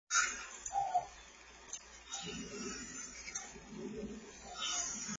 Our whistling spirit is very active recently. He actually asked a question after he whistled last night. Listen with earphones to best hear what is said.